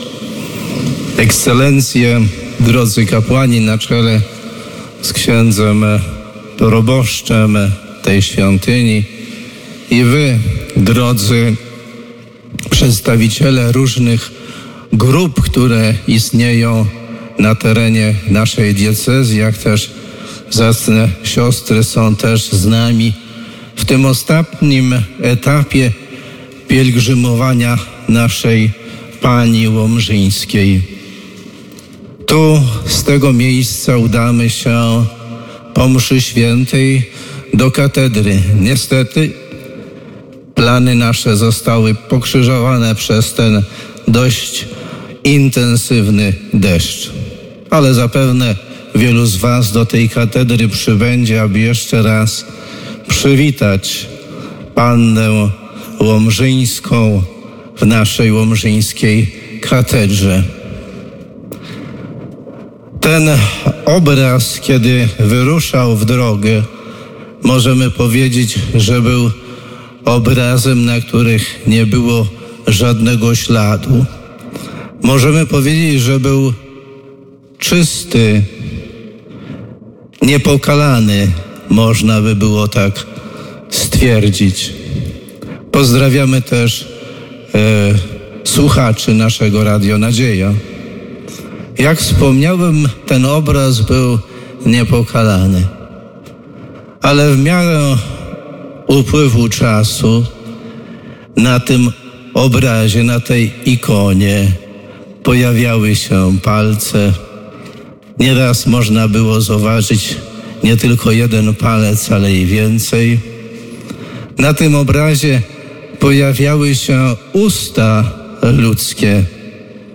Kazanie-Biskupa-Janusza-w-Milosierdziu-na-100-lecie-diec.mp3